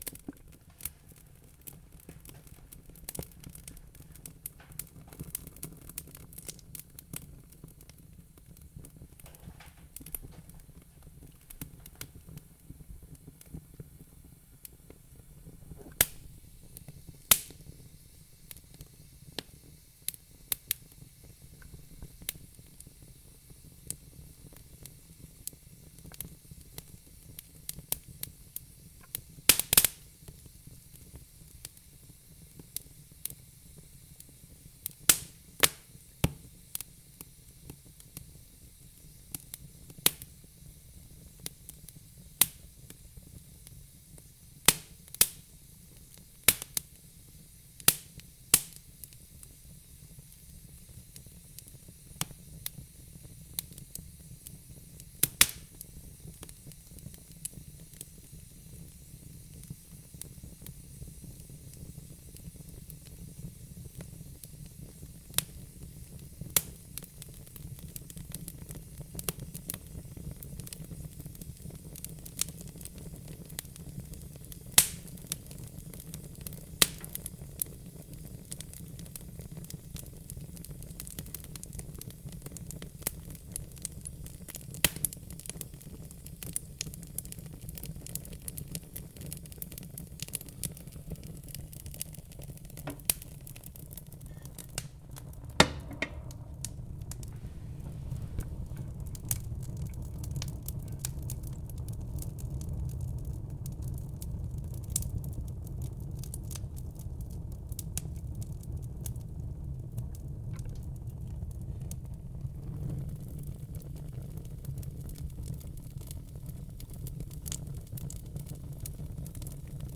fire-1.ogg